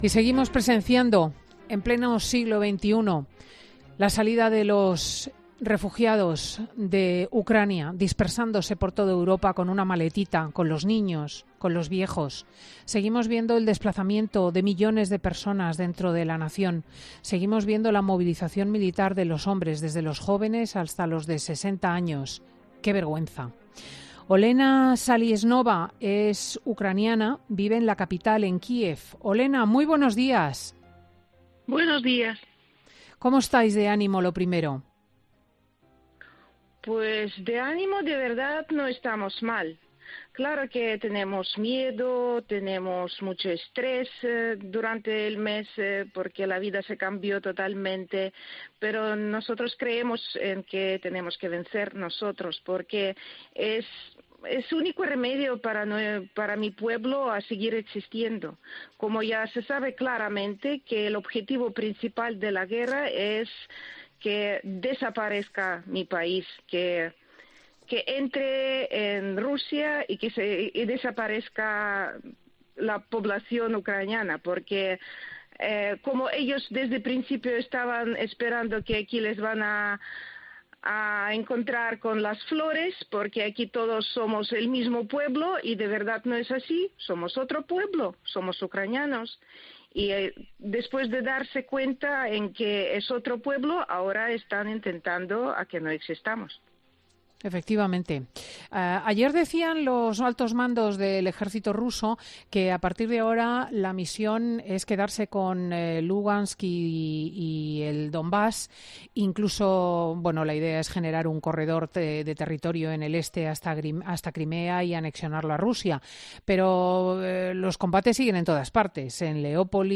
profesora de universidad, relata en COPE cómo está siendo la vida en la capital tras un mes de conflicto armado